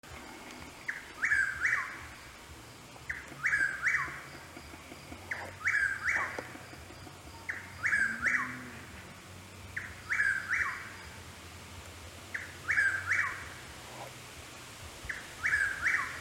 Chuck-will’s-widow